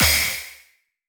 normal-hitfinish.wav